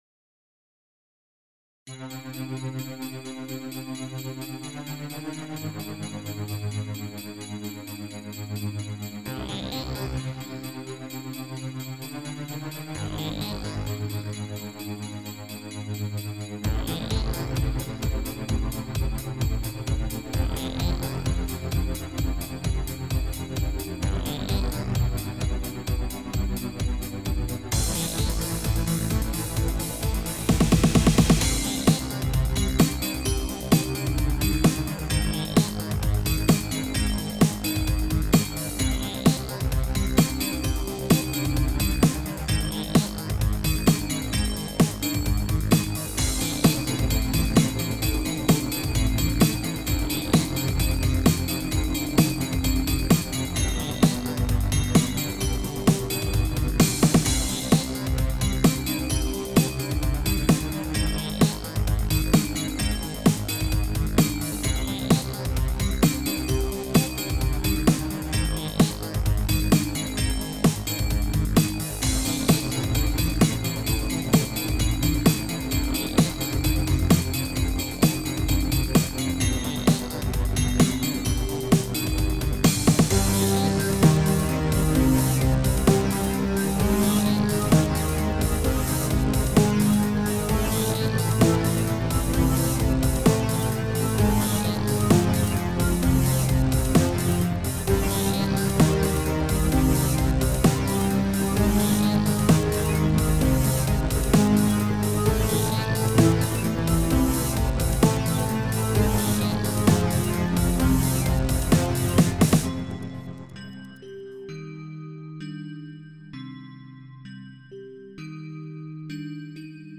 Musiikkikappale ennen miksausta
Työn tarkoituksena oli miksata musiikkikappale, jota tullaan käyttämään videopelissä. Miksaus tehtiin kotistudiossa, joten tavoitteena oli saada aikaan mahdollisimman laadukas lopputulos ilman ammattitason studiota. Miksattaviin ääniraitoihin lukeutuvat rumpusetti, syntetisaattorit ja basso.
Työn tuloksena oli laadukkaasti miksattu teknokappale.
ennen_miksausta.mp3